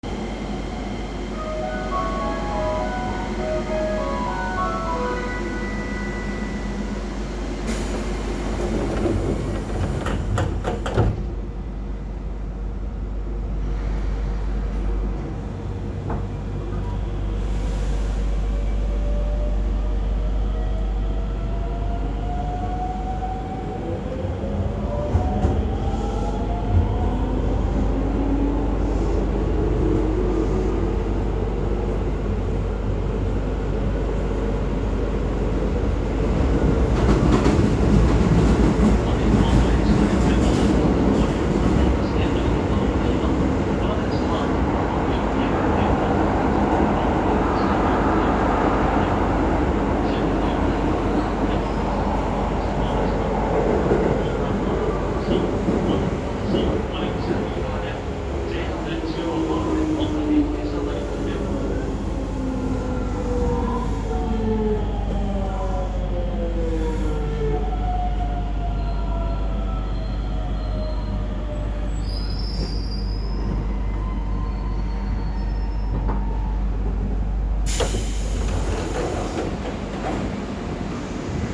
・7300形走行音
【都営浅草線】東日本橋→浅草橋
ごく一般的な東洋GTOで、京成3700形、北総9800形、7800形と音での判別は不可能です（かつてはドアエンジンの音が若干違っていたものの誤差の範囲）。都営浅草線の自動放送には対応していません。